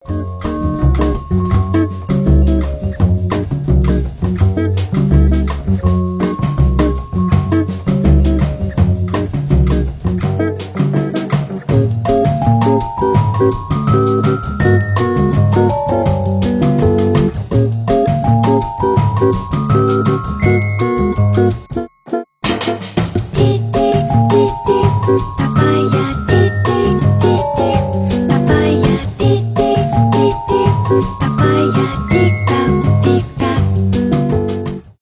lounge , мелодичные
легкие , инструментальные